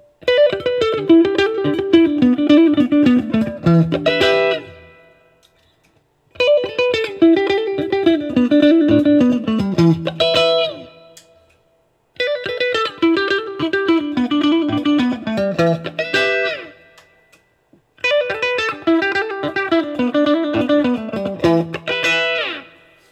All recordings in this section were recorded with an Olympus LS-10.
For each recording, I cycle through all of the possible pickup combinations, those being (in order): neck pickup, both pickups (in phase), both pickups (out of phase), bridge pickup.
Open E